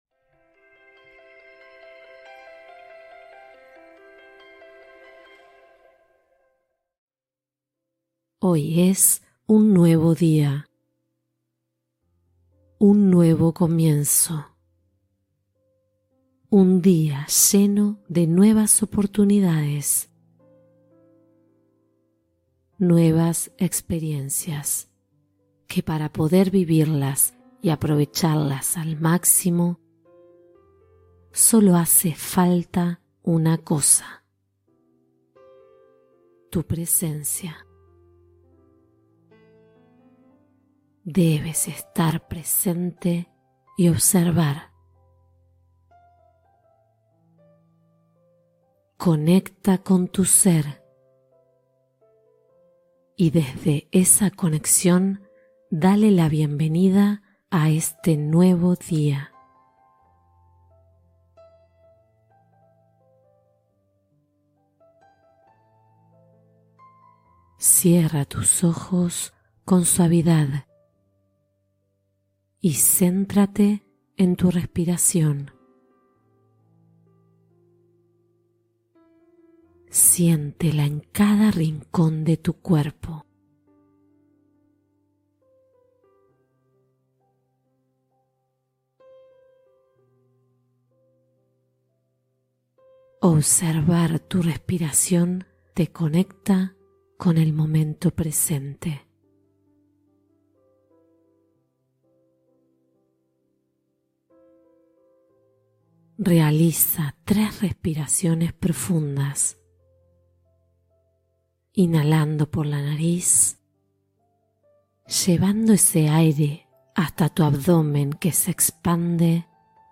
Comienza el Día con Gratitud Activa: Meditación Matutina Positiva